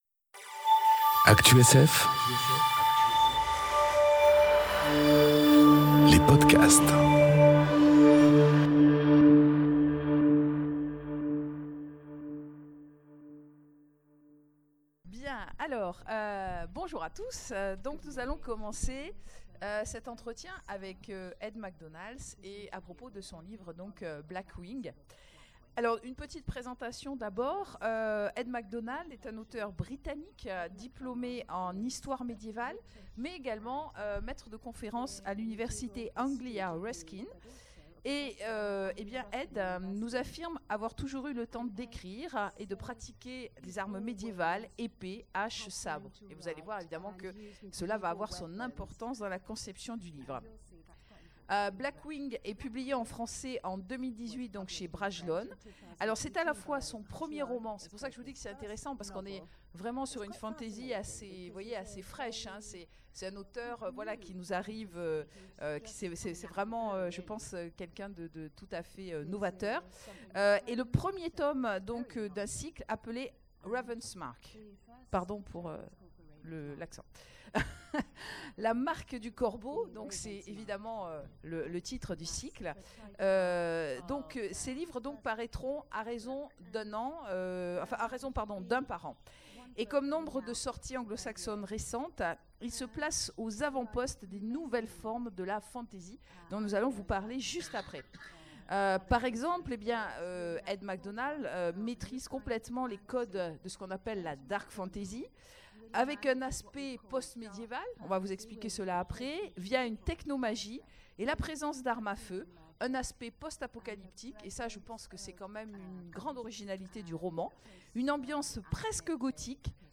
enregistré aux Imaginales 2018
Rencontre avec un auteur